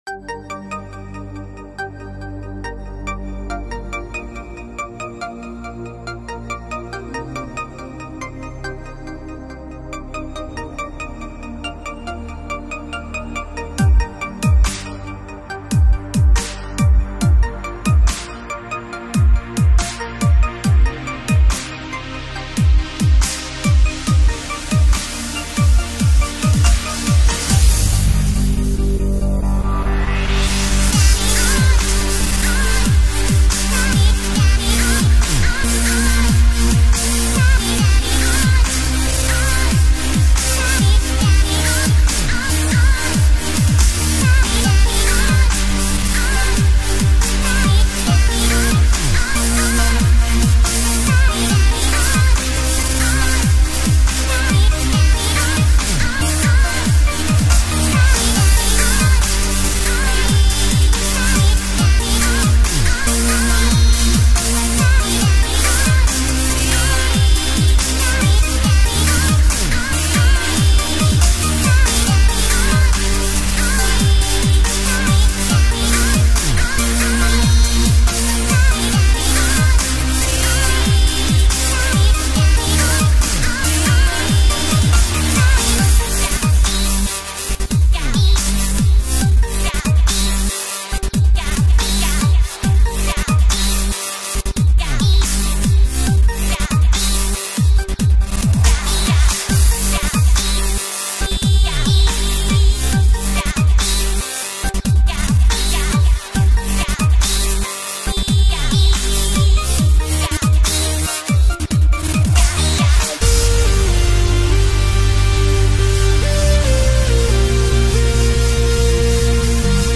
Second try at dubstep!